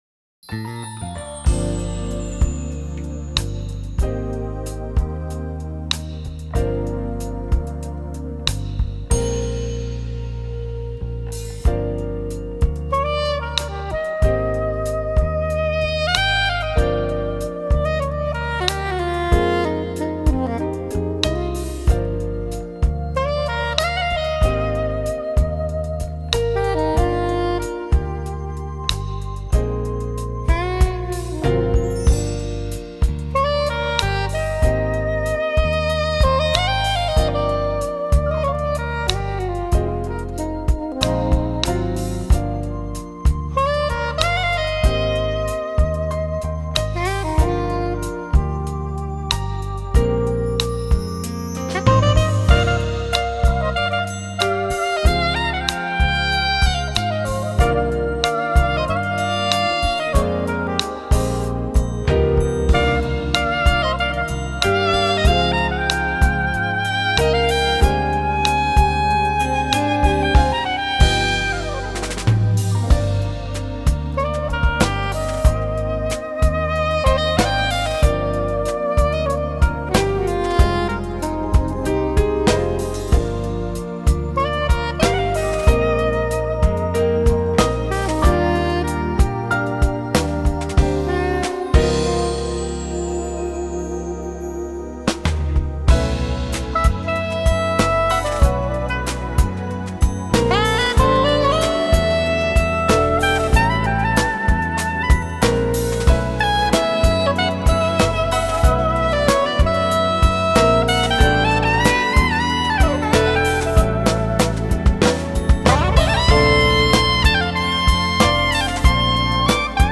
Sax
original smooth jazz compositions
was recorded in Nashville
Drums
Bass
Percussion
Keyboards
Guitar.